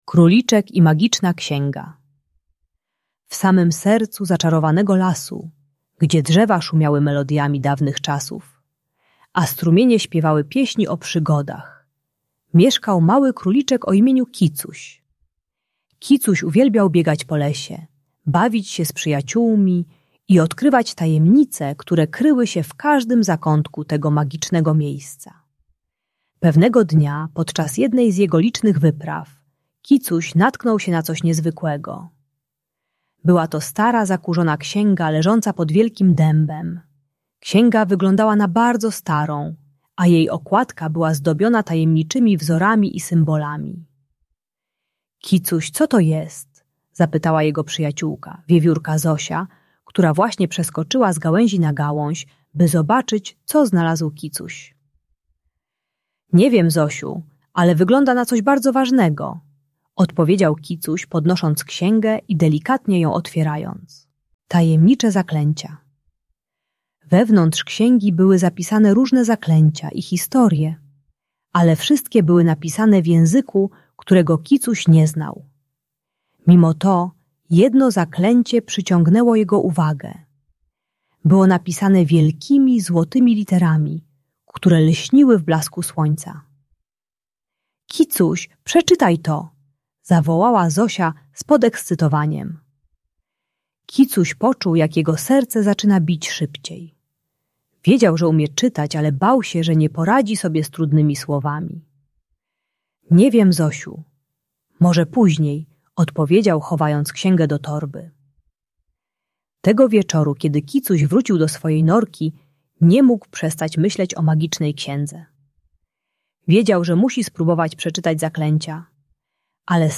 Króliczek i Magiczna Księga - Szkoła | Audiobajka